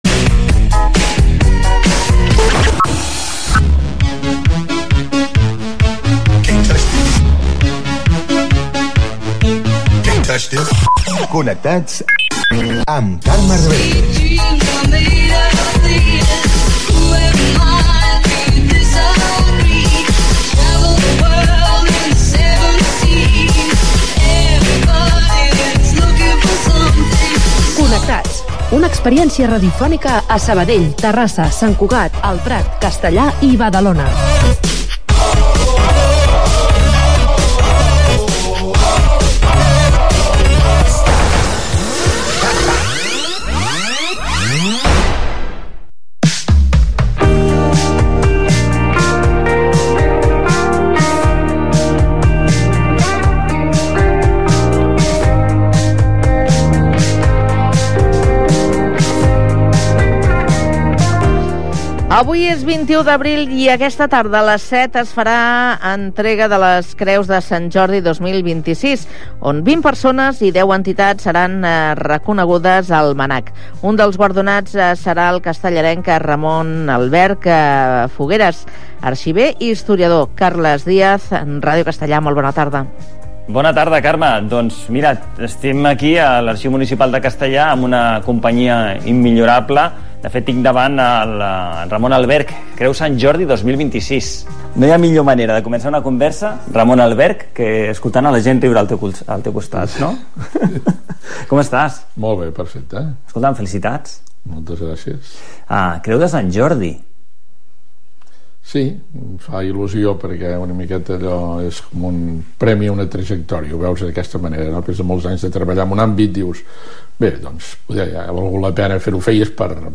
Un magazín de tarda ple d'actualitat i entreteniment.